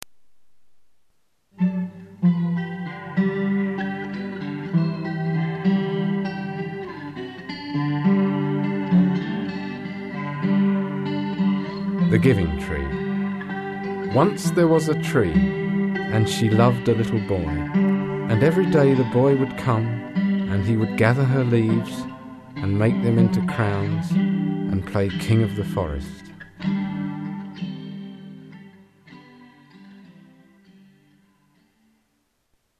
The Giving Tree (Audio Book) by Shel Silverstein